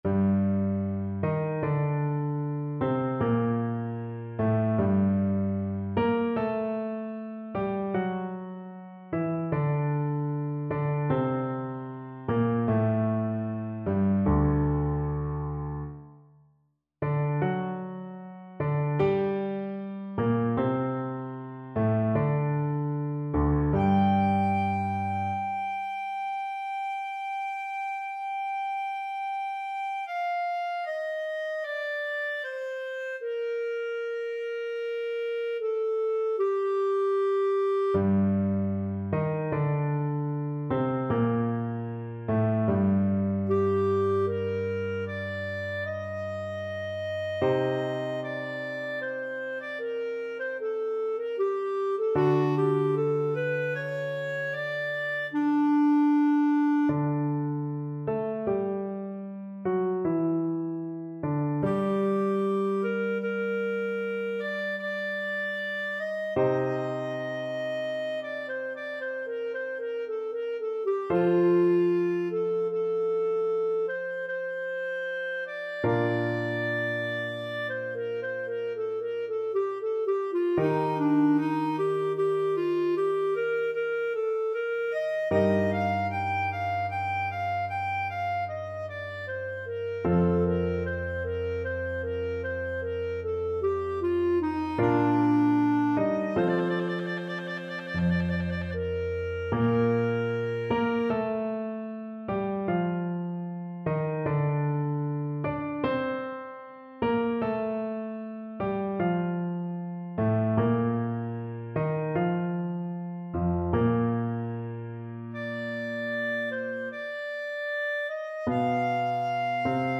Classical Vivaldi, Antonio Bassoon Concerto in G Minor, RV 495, Second Movement Clarinet version
Clarinet
G minor (Sounding Pitch) A minor (Clarinet in Bb) (View more G minor Music for Clarinet )
3/4 (View more 3/4 Music)
II: Largo =38
Classical (View more Classical Clarinet Music)